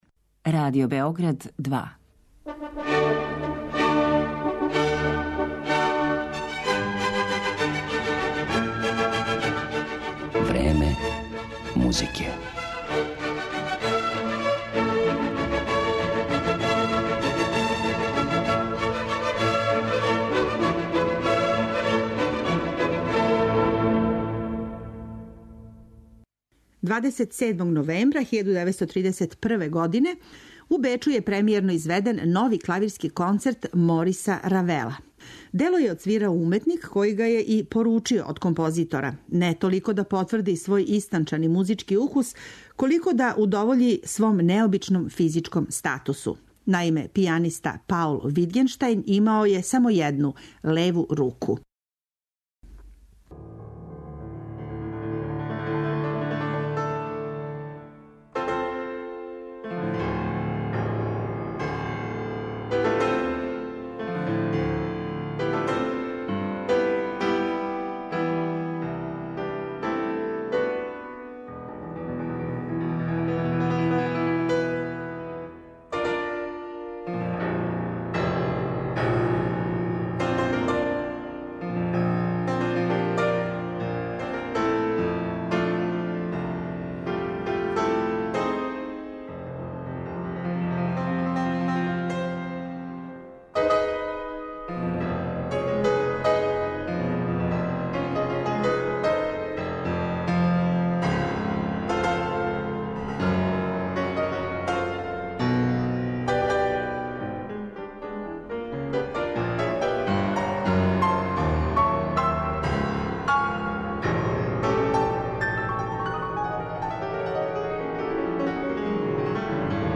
слушаћете концерте за клавир које су њихови аутори написали само за леву руку пијанисте.